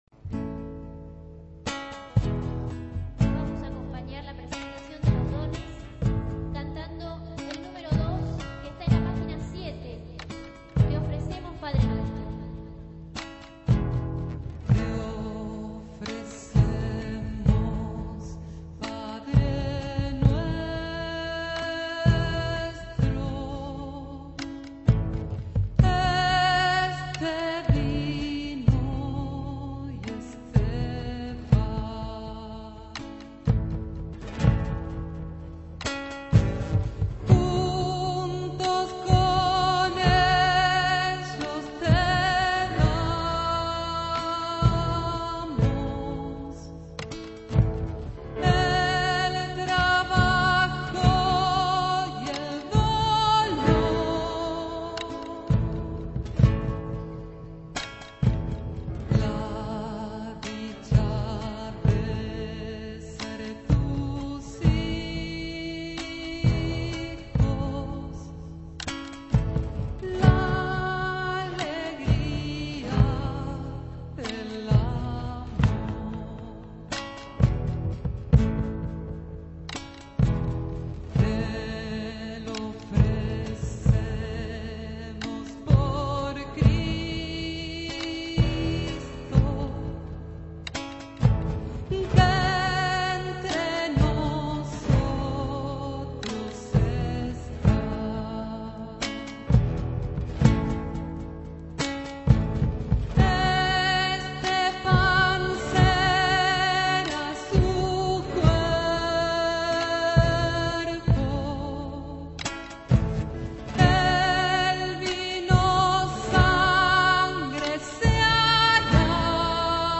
Baguala